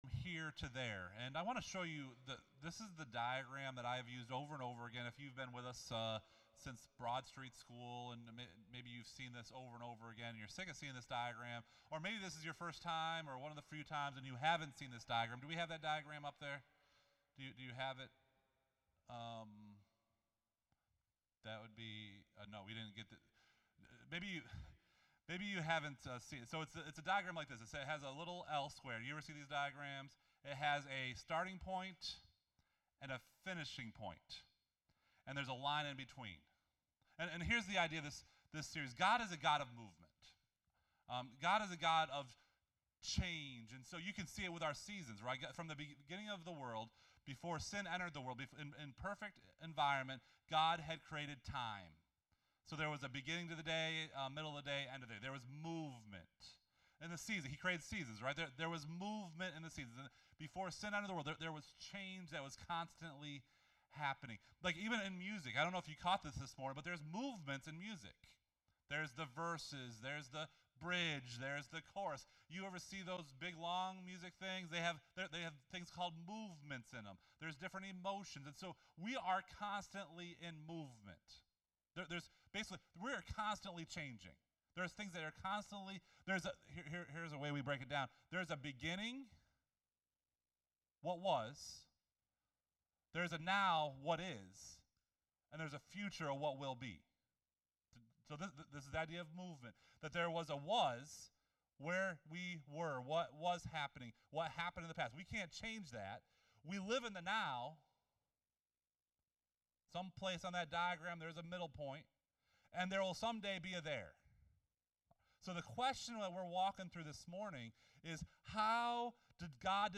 SERMON: 9/10/2017 FROM HERE TO THERE (Week 1)